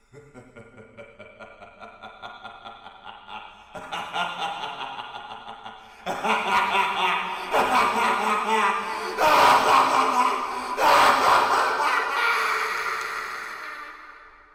Звуки смеха Джокера
Вы можете слушать онлайн или скачать различные вариации его жуткого, пронзительного и безумного хохота в хорошем качестве.